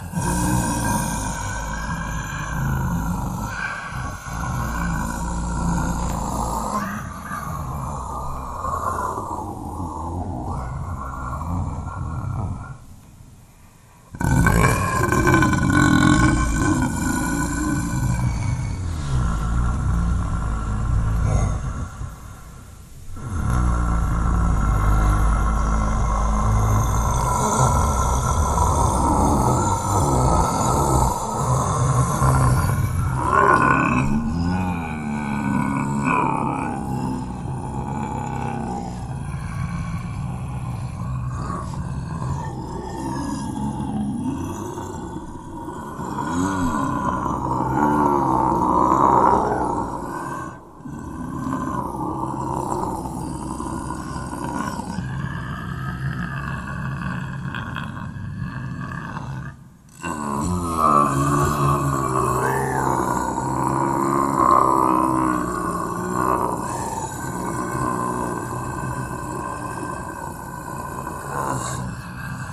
GrumbleVoiceSound1.mp3